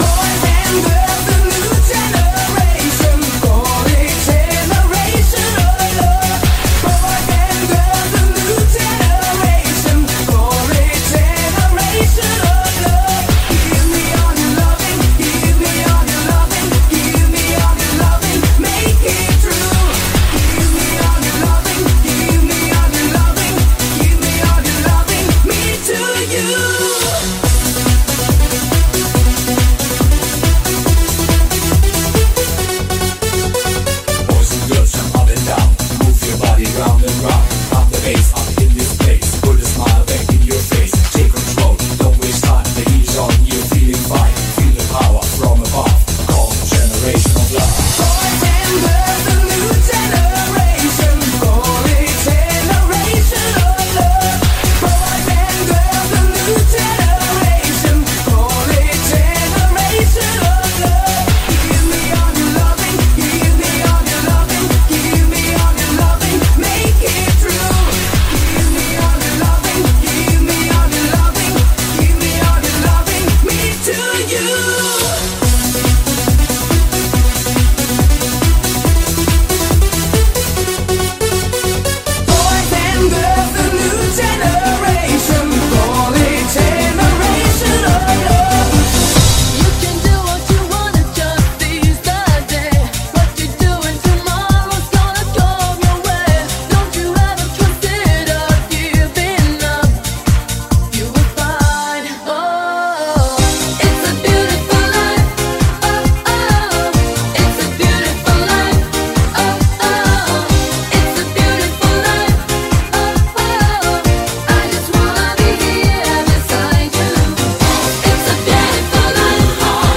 Лучшие танцевальные 90 х __VOL 2 __
Luchshie-tancevaljqnye-90-h-__VOL-2-__.mp3